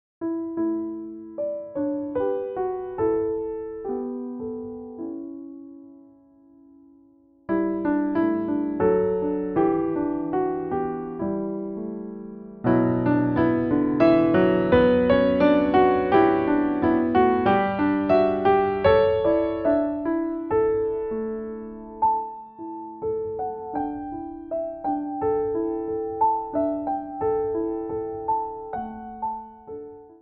Description:Classical; classical/Jazz
Instrumentation:Piano solo